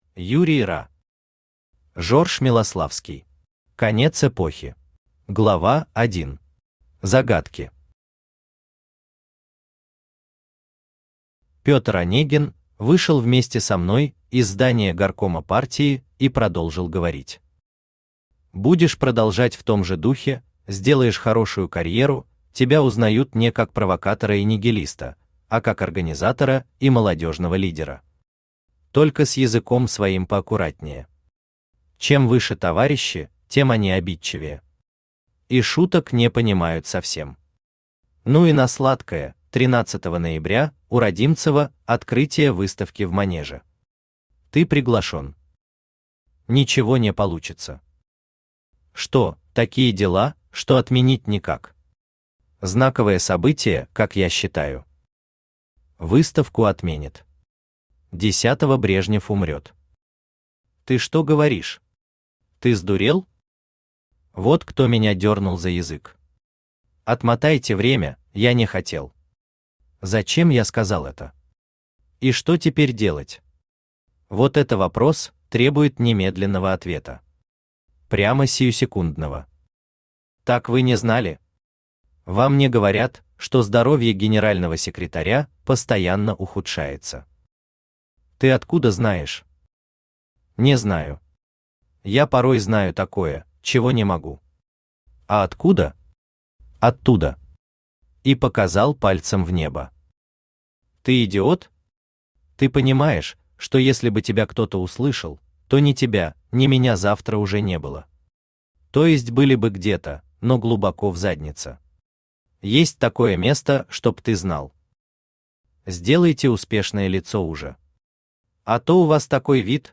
Aудиокнига Жорж Милославский. Конец эпохи Автор Юрий Ра Читает аудиокнигу Авточтец ЛитРес. Прослушать и бесплатно скачать фрагмент аудиокниги